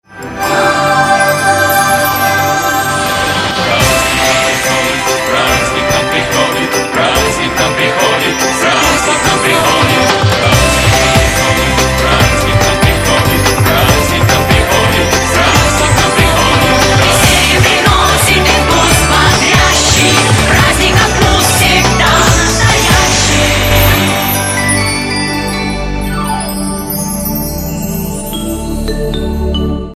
Новогодний
рингтон